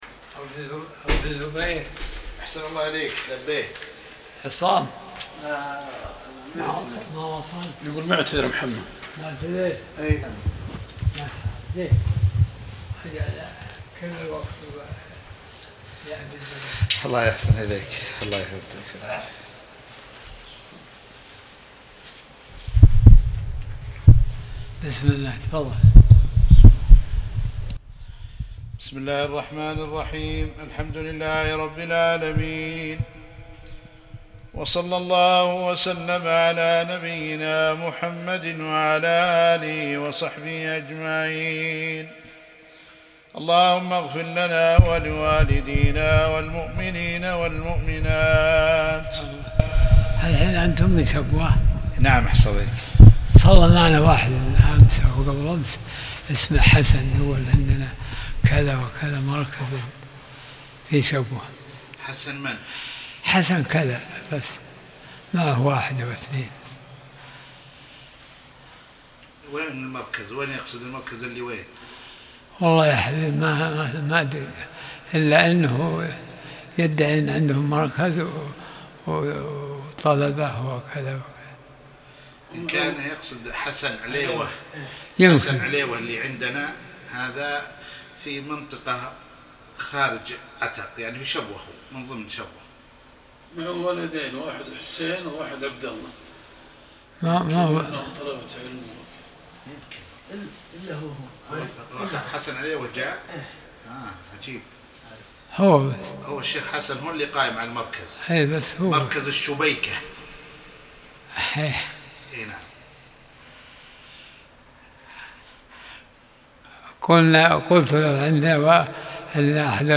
درس الأربعاء 52